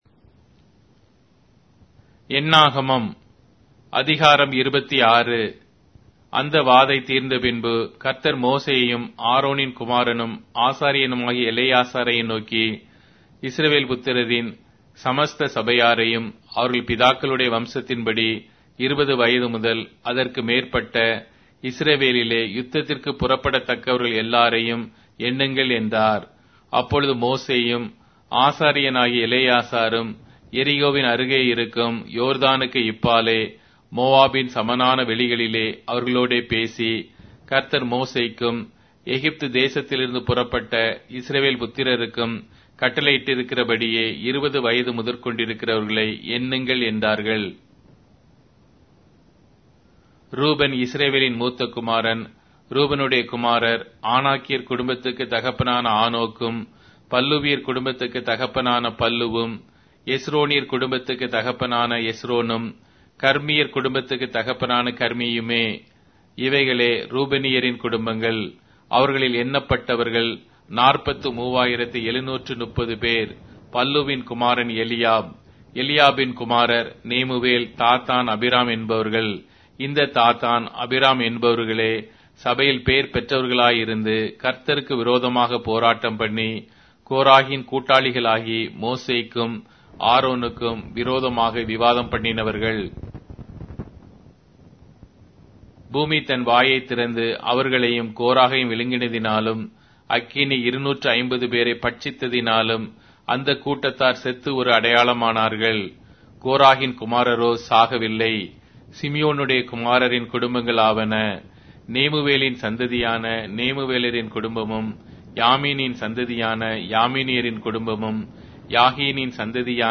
Tamil Audio Bible - Numbers 28 in Mrv bible version